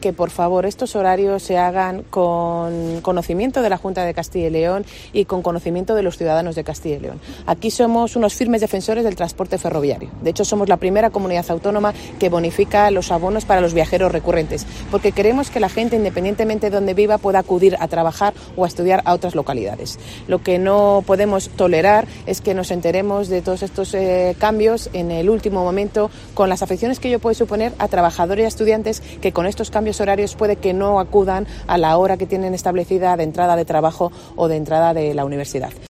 AUDIO: Es la consejera de Movilidad y Transformación Digital de la Junta de Castilla y León